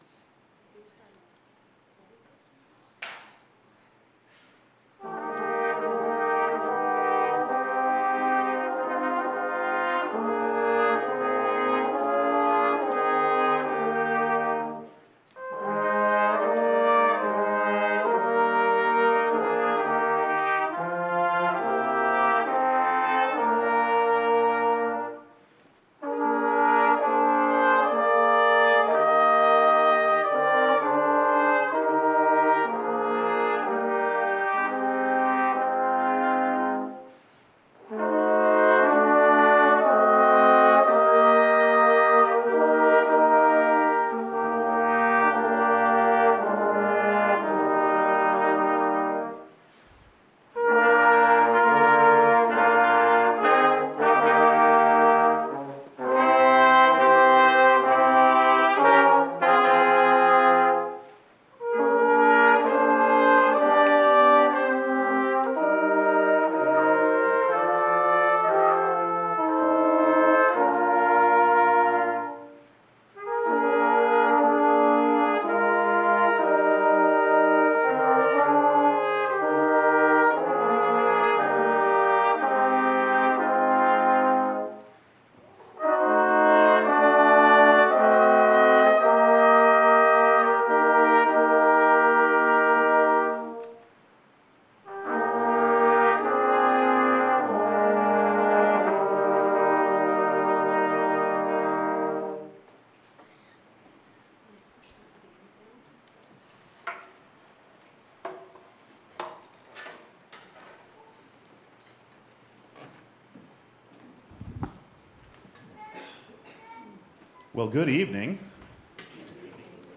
Libertas Chorale - Robinson Baptist